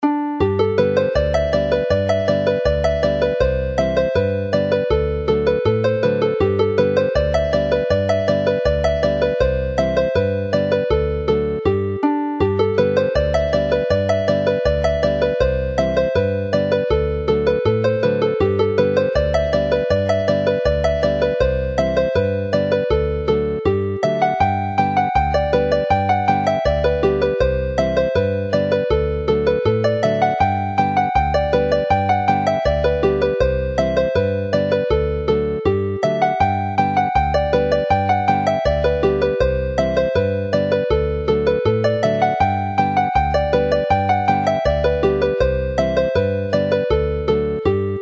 Alawon Cymreig - Set yr Aradr - Welsh folk tunes to play